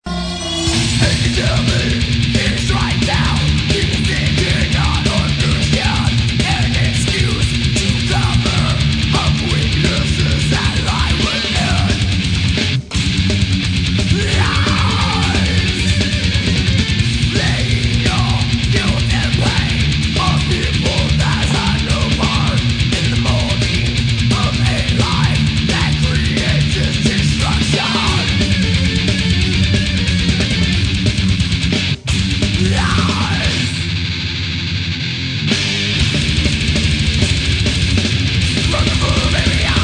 All the samples on this page are 22khz/44khz,16bit,stereo.